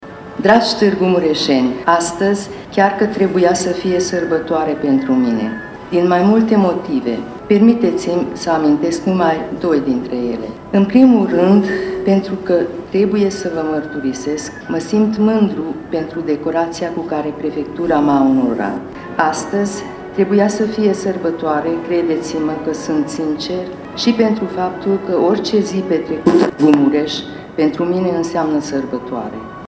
Ceremonia de premiere a fost organizată în Sala de Oglinzi a Palatului Culturii din Tîrgu-Mureș.